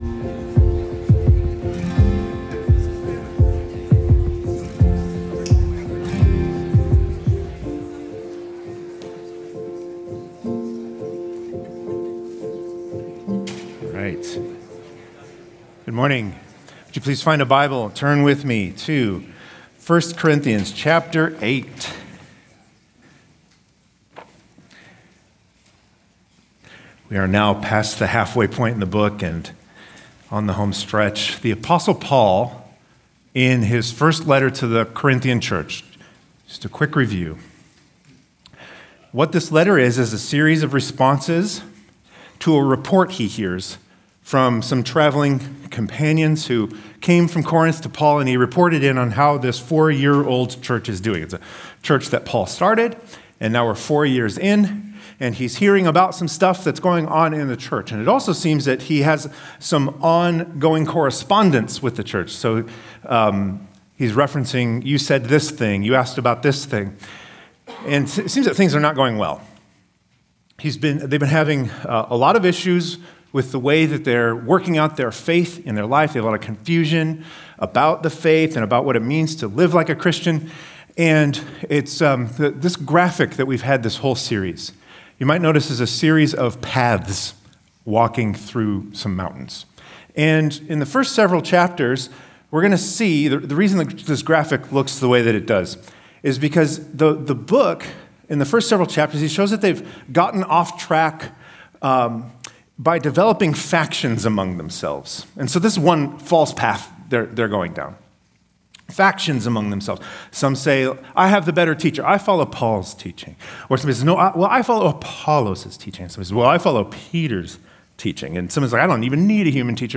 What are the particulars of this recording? Stonebrook Sunday AM